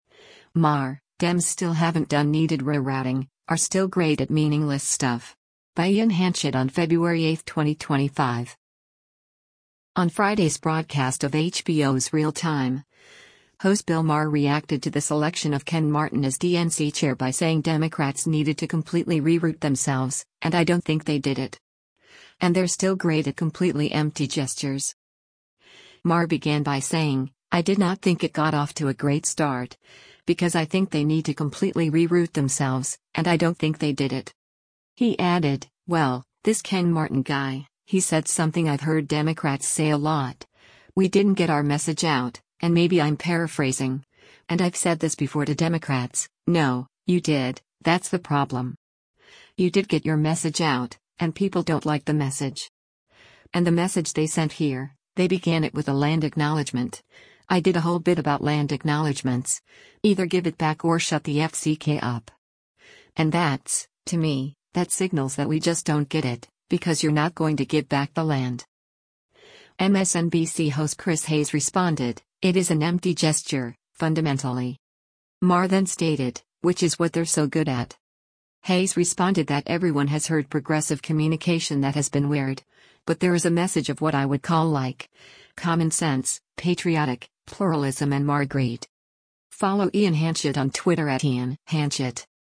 MSNBC host Chris Hayes responded, “It is an empty gesture, fundamentally.”